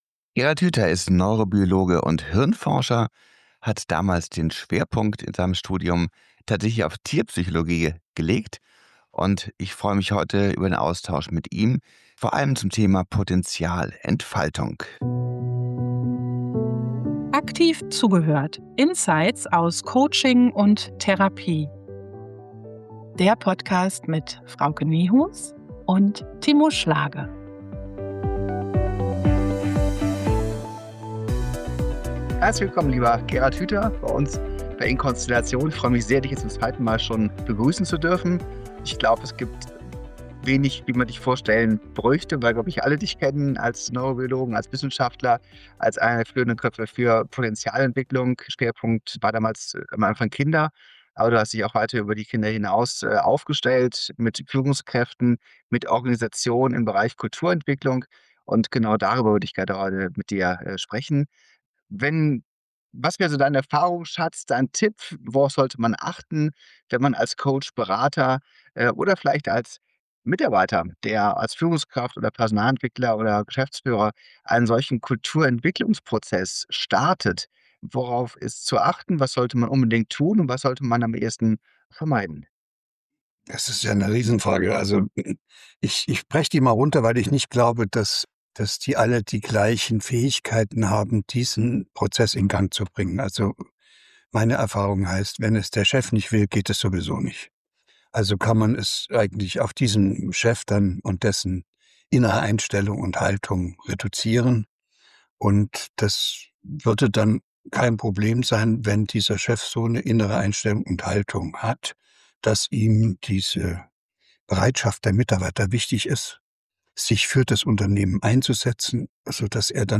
Interview mit Gerald Hüther - Potenzialentfaltung, Gehirn & Menschsein ~ Aktiv Zugehört - Insights aus Coaching & Therapie Podcast